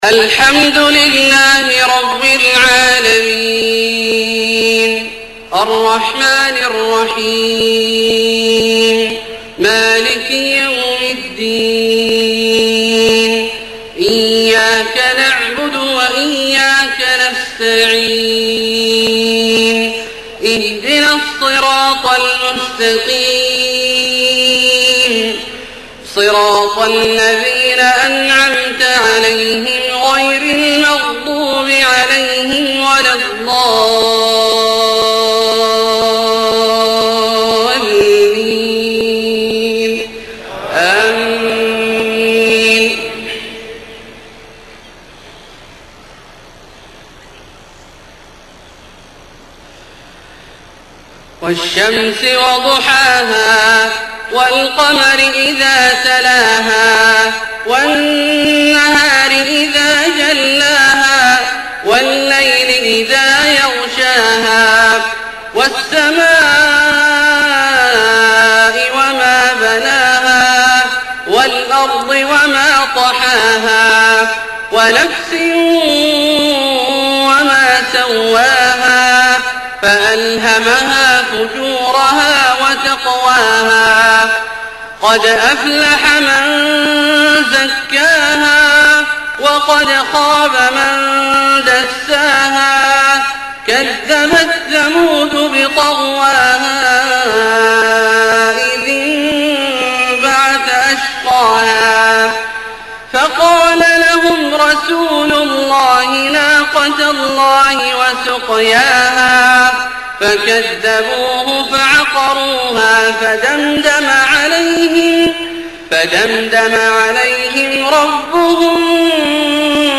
صلاة المغرب 9-8-1429 سورتي الشمس و التين > ١٤٢٩ هـ > الفروض - تلاوات عبدالله الجهني